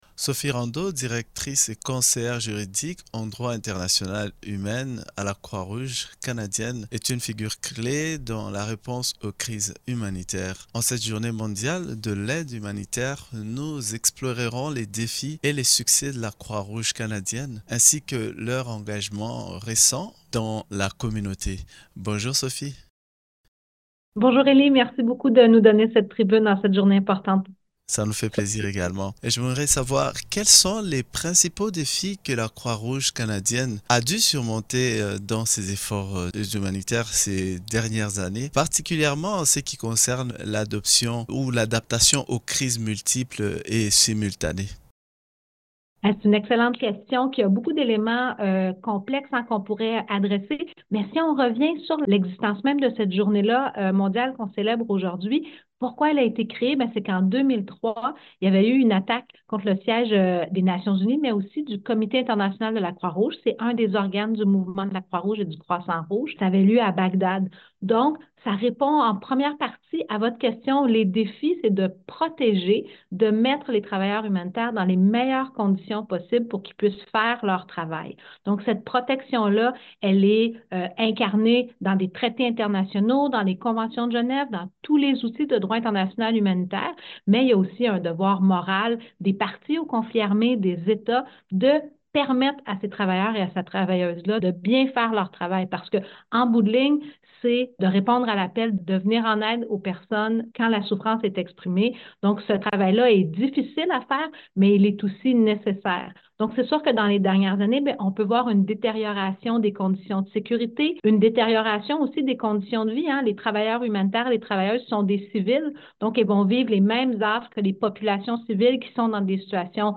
Entrevue-Croix-rouge_mixdown-1.mp3